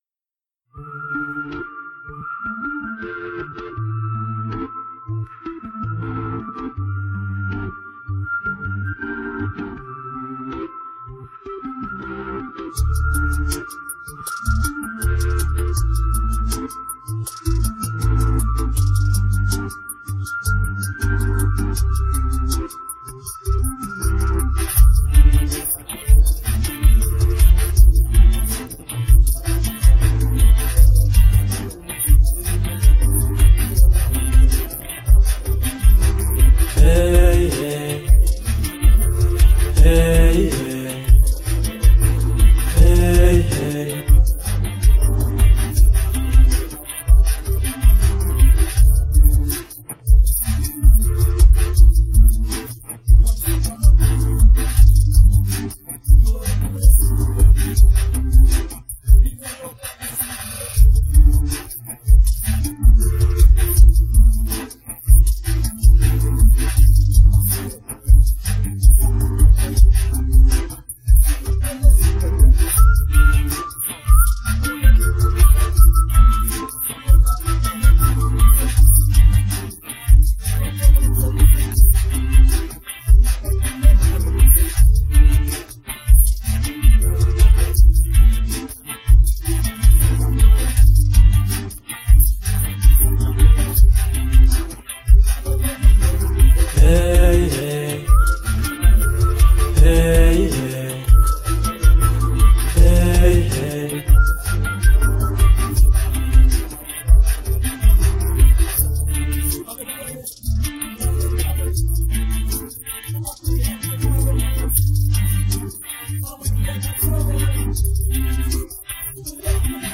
Play Karaoke & Rap with Us